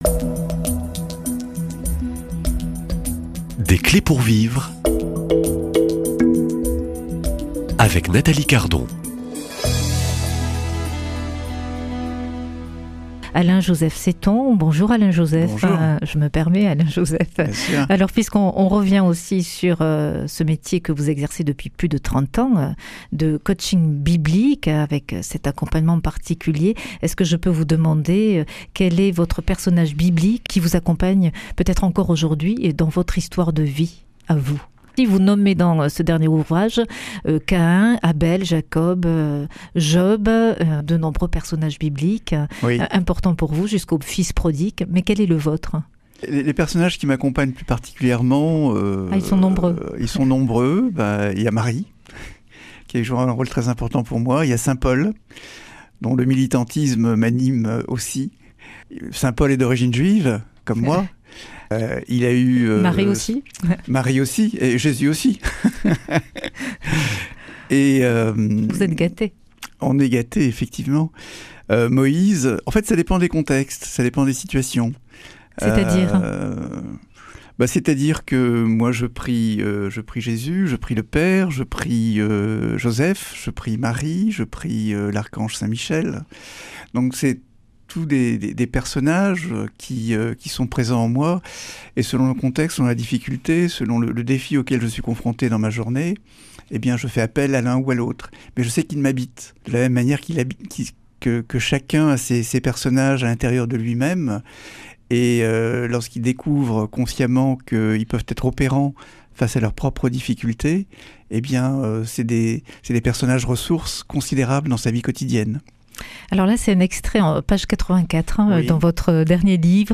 Invité :
Une émission présentée par